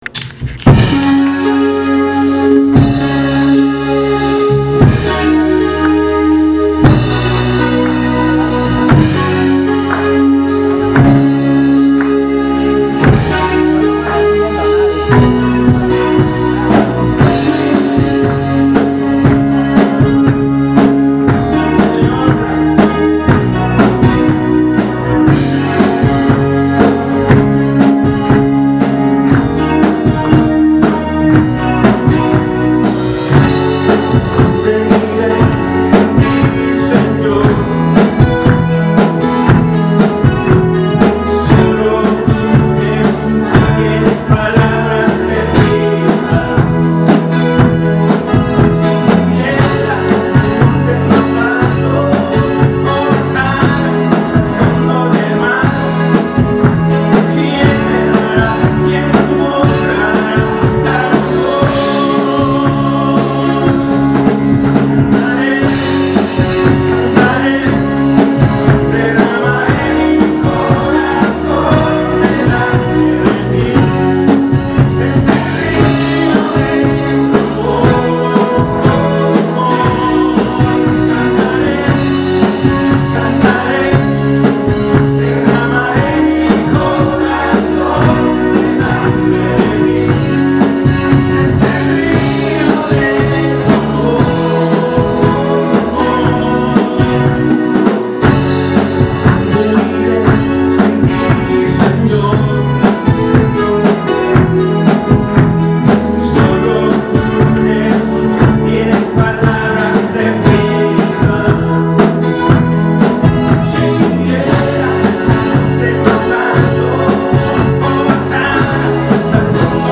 Alabanzas — Iglesia Bautista Maranatha
Cantando con júbilo al Señor